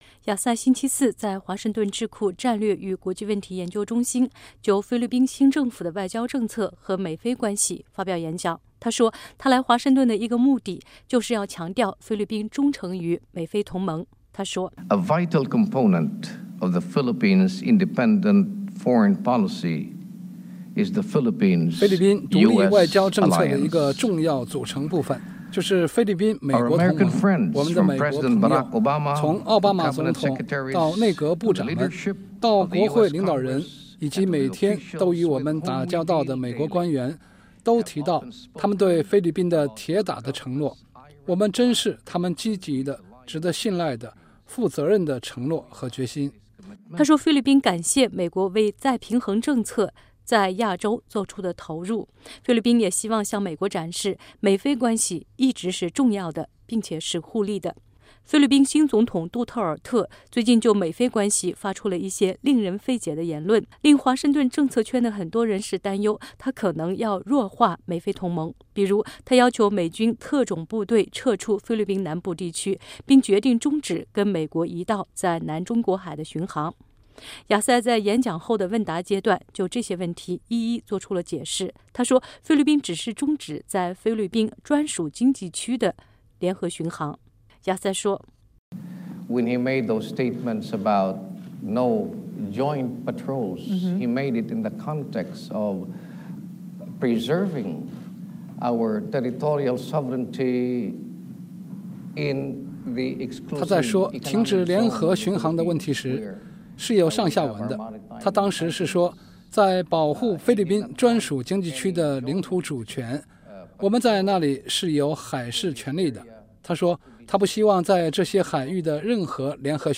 菲律宾外交部长佩费克托·雅赛星期四在华盛顿智库战略与国际研究中心（CSIS）就菲律宾新政府的外交政策和美菲关系发表演讲。